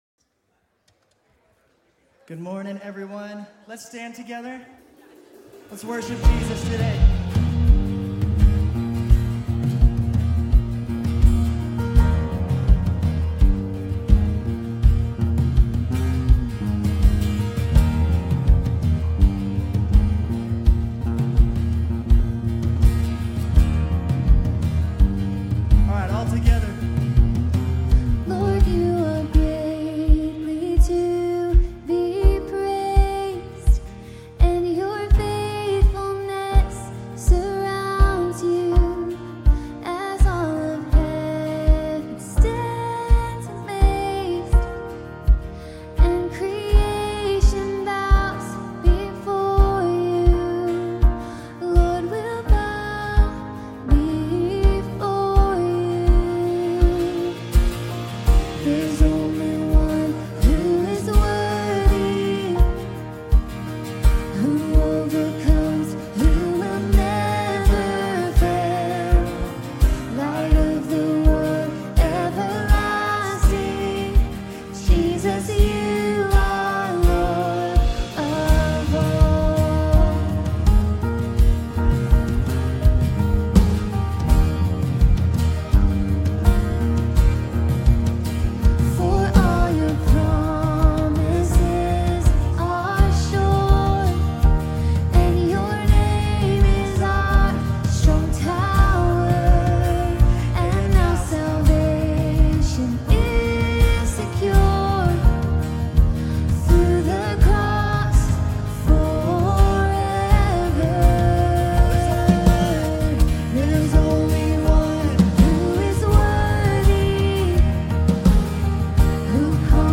Grace Community Church University Blvd Campus Sermons International Day of Prayer for the Persecuted Church Nov 03 2024 | 00:26:46 1x 00:00 / 00:26:46 Subscribe Share RSS Feed Share Link Embed